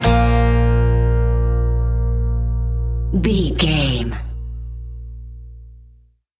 Ionian/Major
B♭
acoustic guitar
bass guitar
banjo
country rock
bluegrass
happy
uplifting
driving
high energy